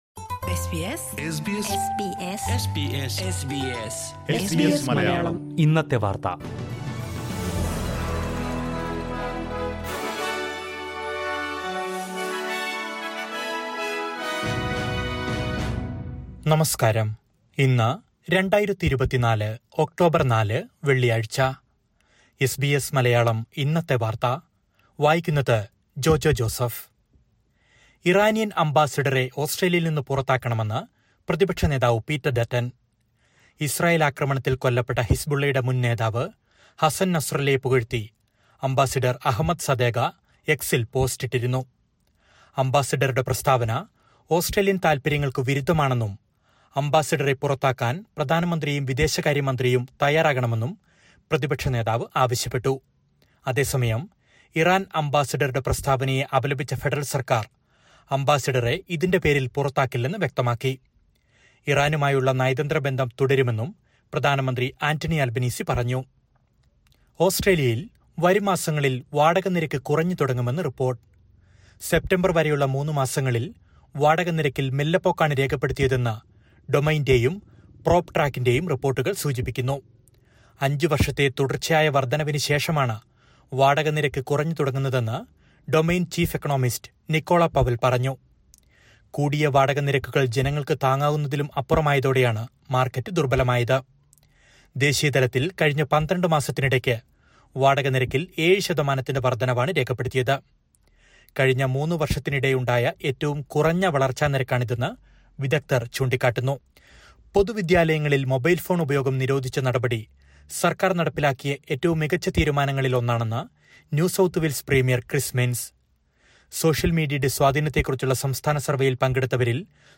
2024 ഒക്ടോബര്‍ നാലിലെ ഓസ്‌ട്രേലിയയിലെ ഏറ്റവും പ്രധാന വാര്‍ത്തകള്‍ കേള്‍ക്കാം...